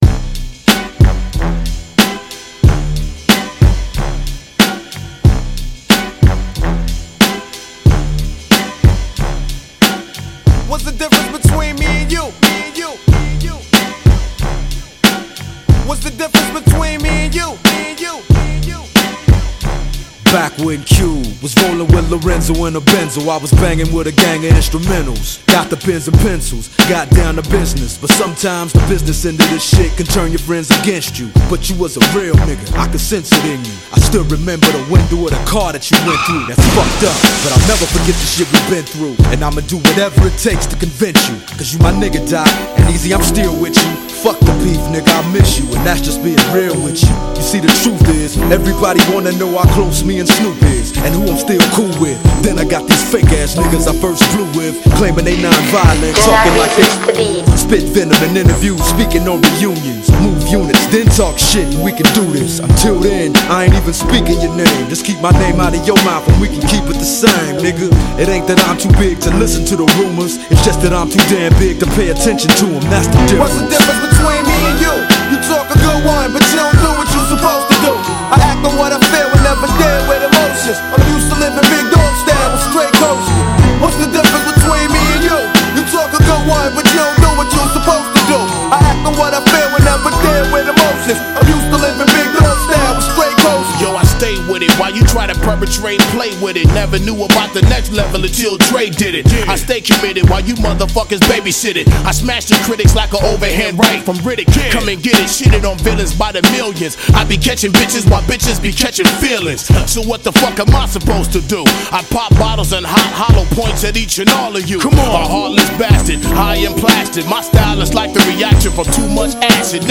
HipHop 90er